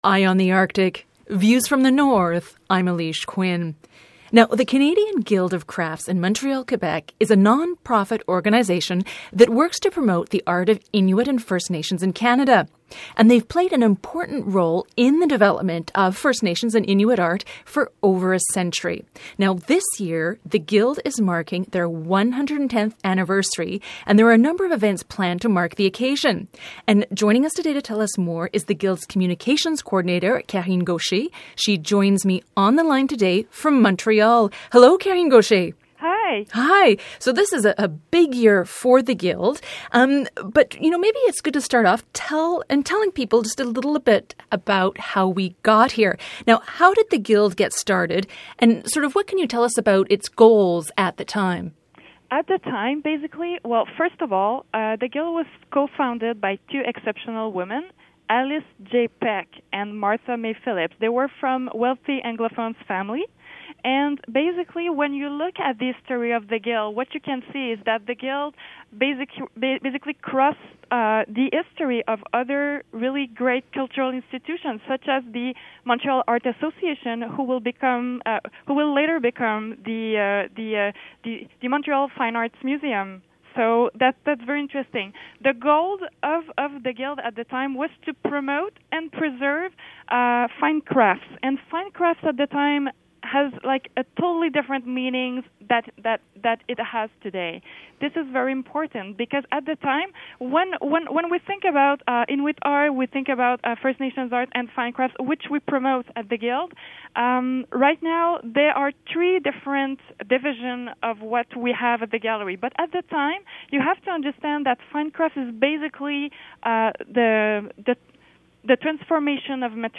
Feature Interview: Canadian Guild of Crafts celebrates 110 years